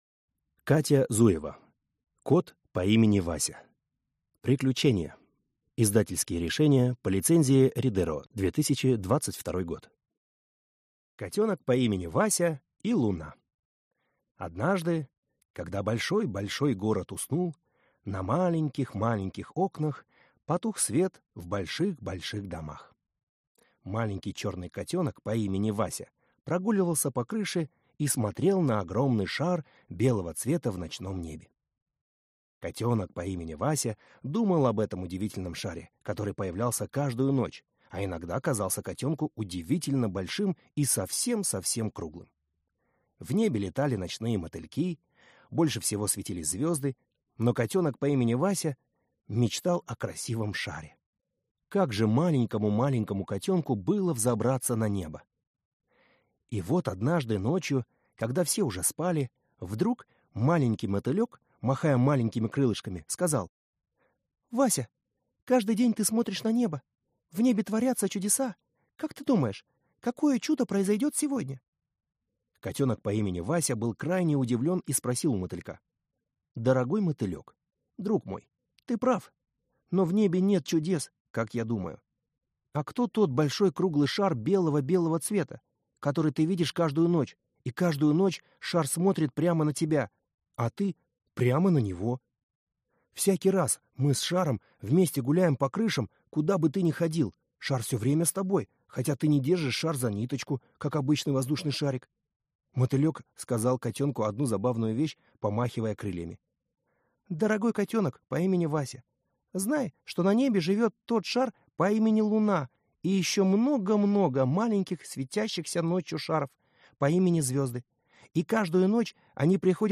Аудиокнига Кот по имени Вася. Приключения | Библиотека аудиокниг